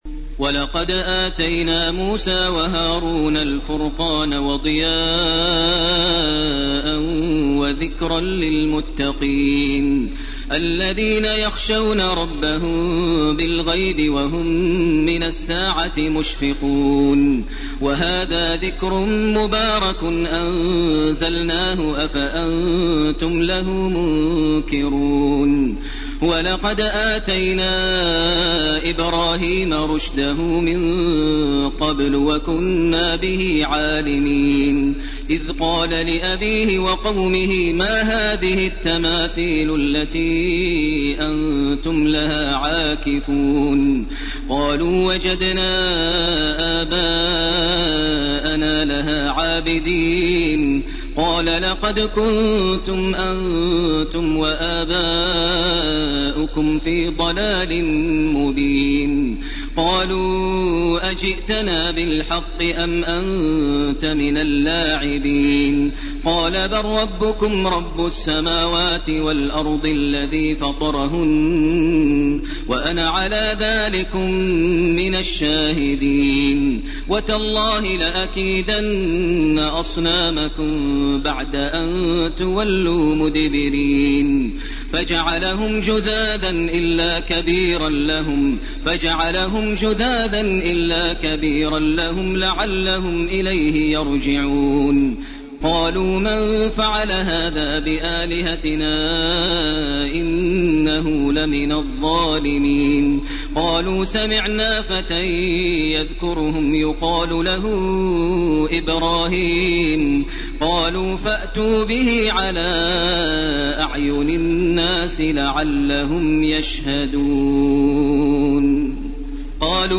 سورة الأنبياء 48 الي أخرها > تراويح ١٤٢٩ > التراويح - تلاوات ماهر المعيقلي